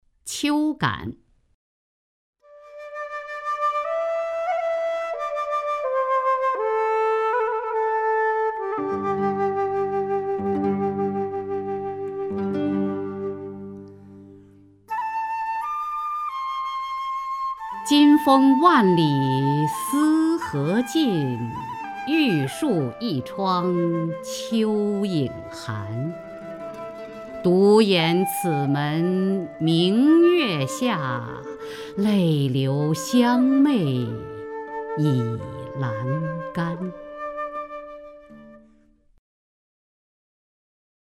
雅坤朗诵：《秋感》(（唐）杜牧) （唐）杜牧 名家朗诵欣赏雅坤 语文PLUS